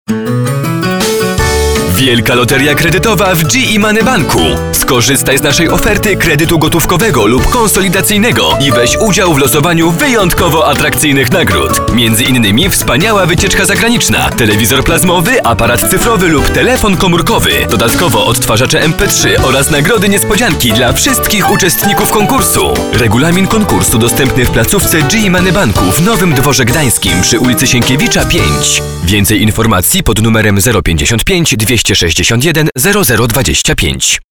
Professioneller polnischer Sprecher für TV/Rundfunk/Industrie.
Sprechprobe: Industrie (Muttersprache):